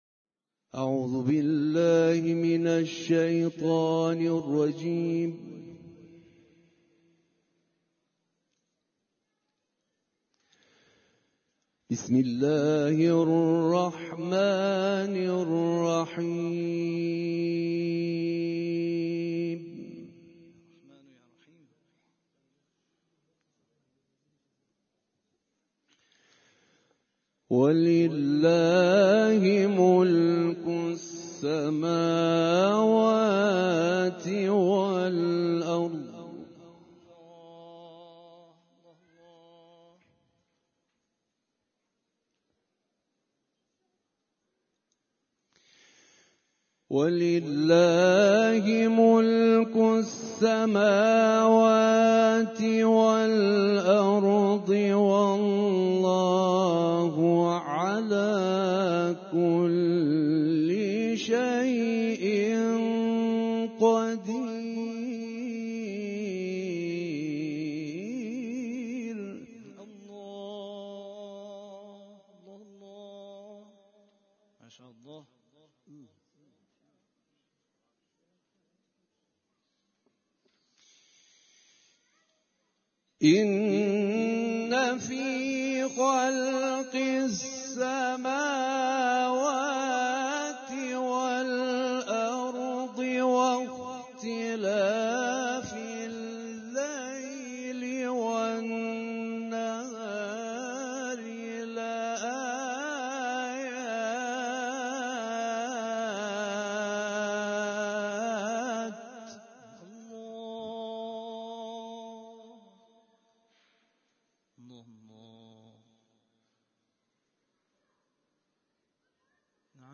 گزارش صوتی دویست و شصتمین کرسی تلاوت و تفسیر قرآن کریم - پایگاه اطلاع رسانی ضیافت نور